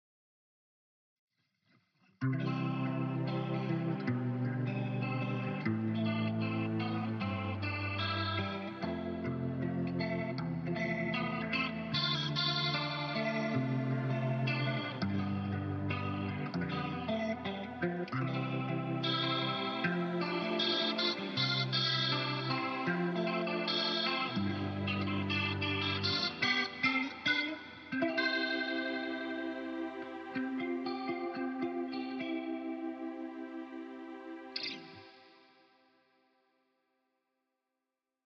The most important thing is that polyphonic tracking sounds rather musical, as if a keyboard player is playing together with you, peeping at your chords